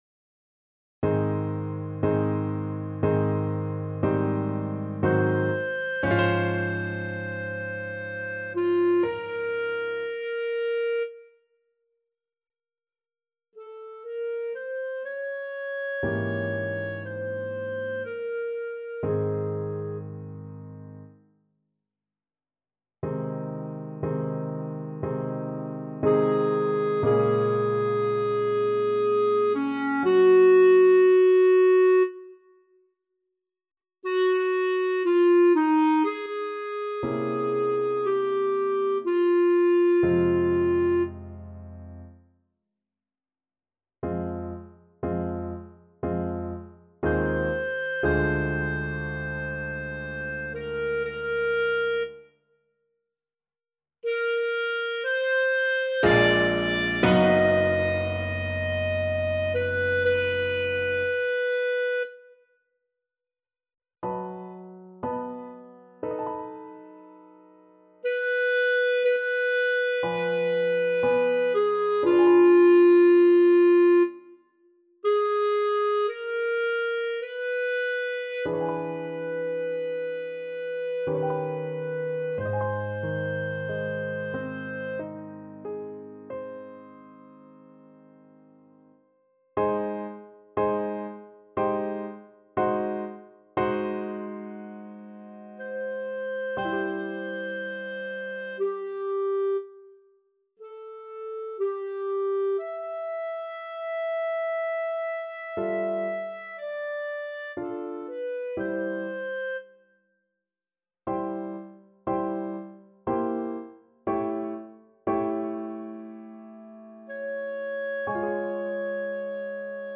Clarinet version
~ = 60 Langsam, leidenschaftlich
3/4 (View more 3/4 Music)
Clarinet  (View more Intermediate Clarinet Music)
Classical (View more Classical Clarinet Music)